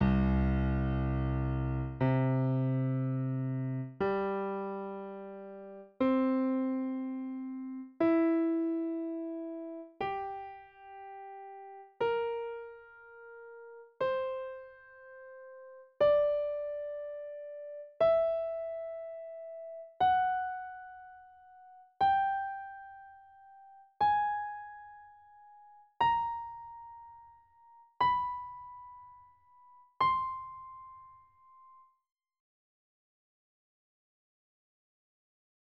倍音a     倍音 (Harmonic over-tone)
Fig.006a 倍音の1例 (下記のFig.006bと同じ譜です)
over-tone.wav